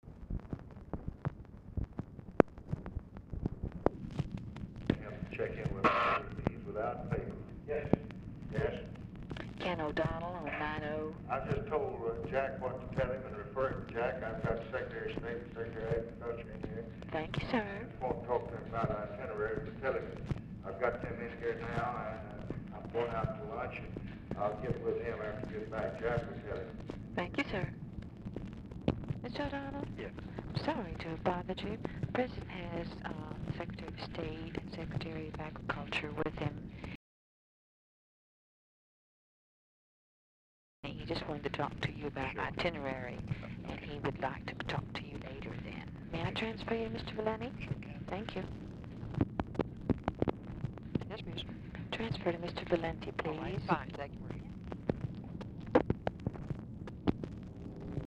Telephone conversation # 4640, sound recording, LBJ and KEN O'DONNELL, 8/3/1964, time unknown | Discover LBJ
Format Dictation belt
Location Of Speaker 1 Oval Office or unknown location
Specific Item Type Telephone conversation